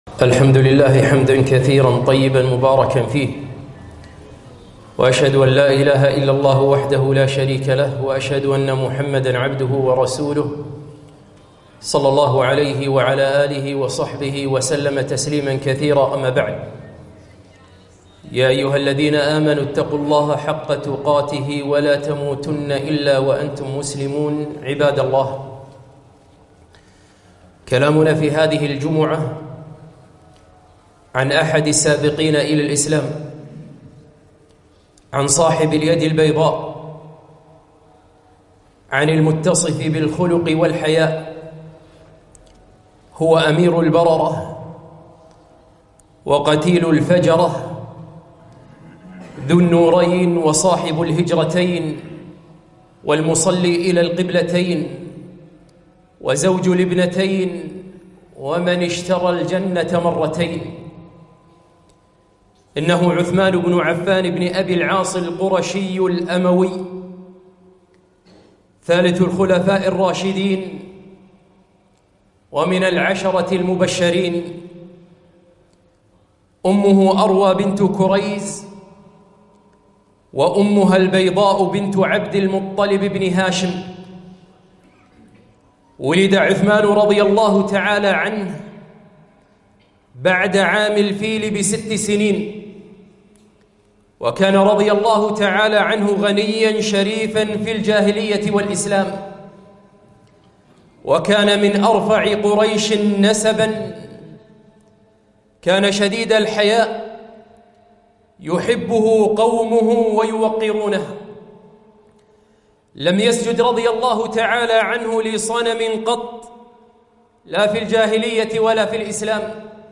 خطبة - عثمان بن عفان - رضي الله عنه